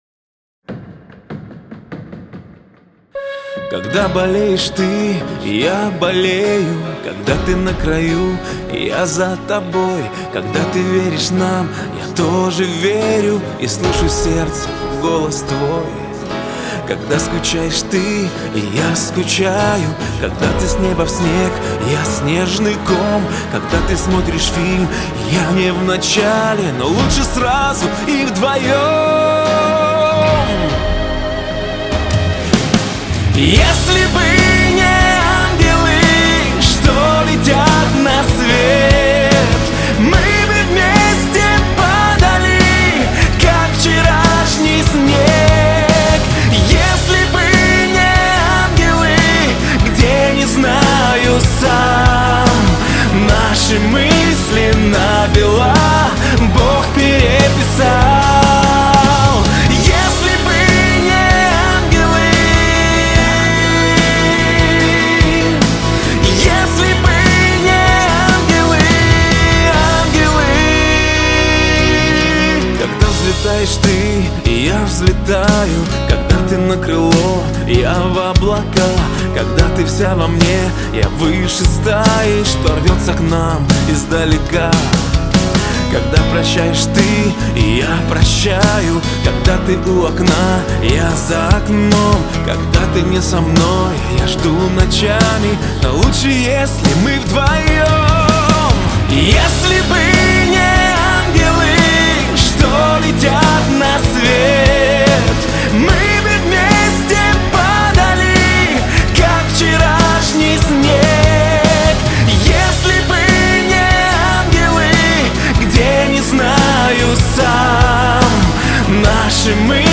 трогательная поп-баллада